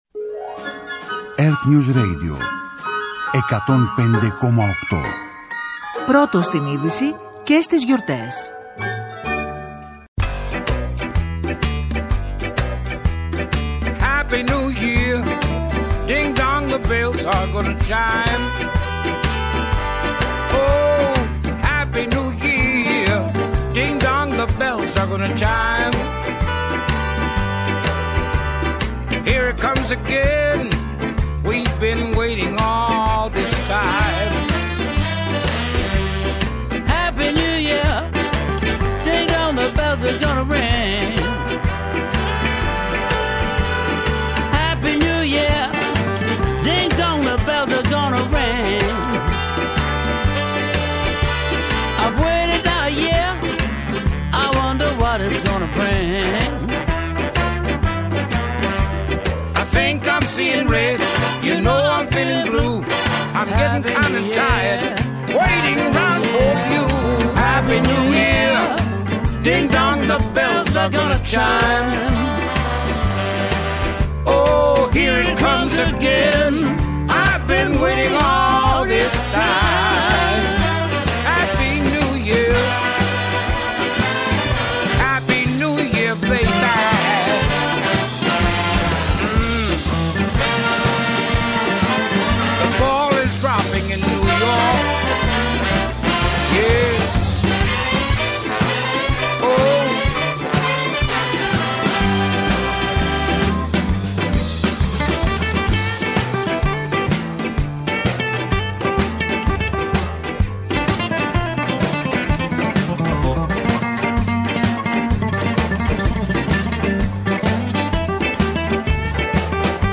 Μια εορταστική εκπομπή όπου η τηλεόραση συναντά το ραδιόφωνο, η καλή διάθεση συναντά τις αλήθειες της καθημερινότητας και η συζήτηση γίνεται τόσο ζωντανή όσο και η μέρα επιβάλλει !